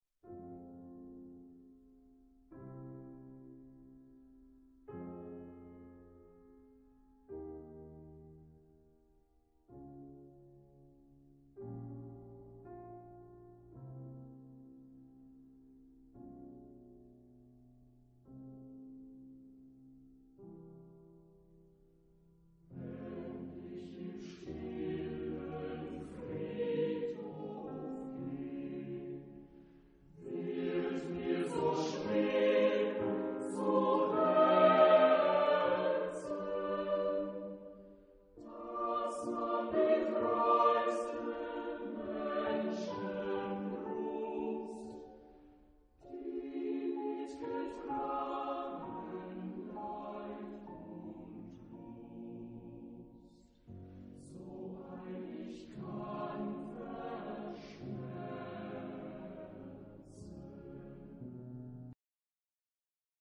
Género/Estilo/Forma: Coro ; Profano ; Romántico
Tipo de formación coral: SATB  (4 voces Coro mixto )
Instrumentos: Piano (1)